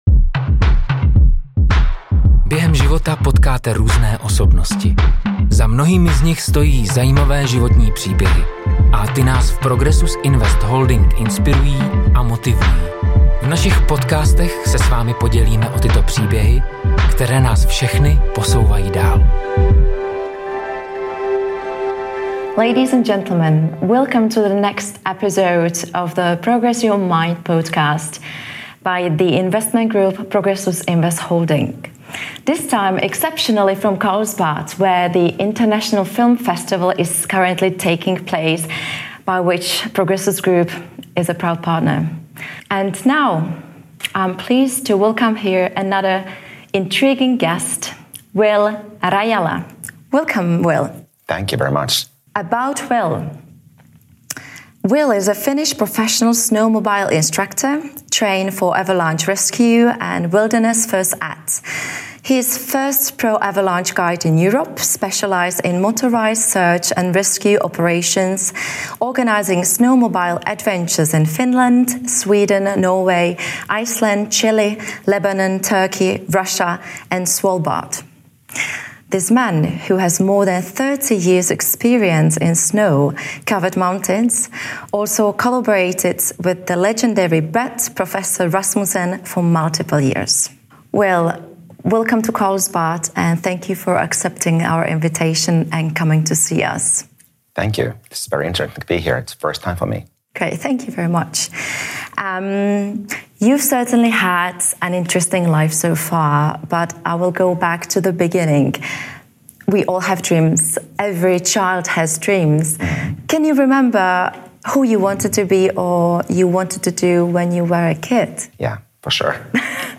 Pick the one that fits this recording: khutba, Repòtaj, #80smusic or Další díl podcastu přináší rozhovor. Další díl podcastu přináší rozhovor